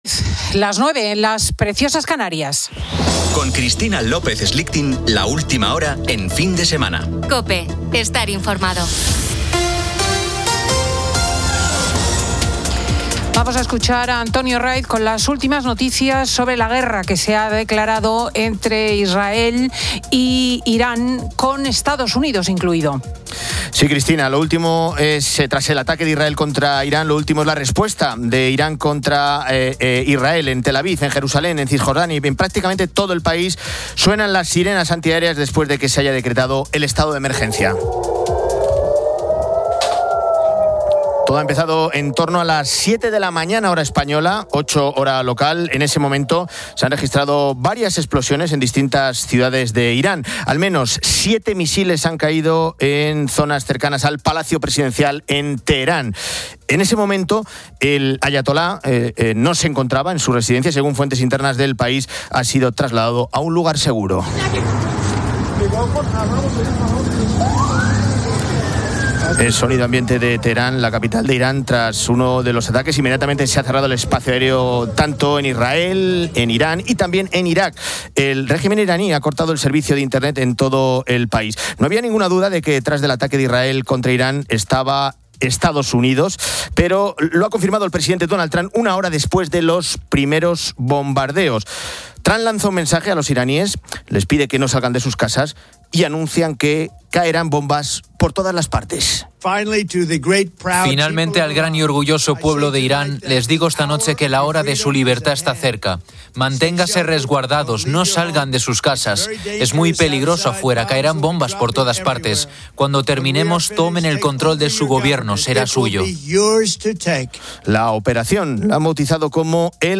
Fin de Semana 10:00H | 28 FEB 2026 | Fin de Semana Editorial de Cristina López Schlichting, analizando el ataque masivo de Israel y EEUU sobre Irán.
Antonio Jiménez analiza con Cristina la actualidad.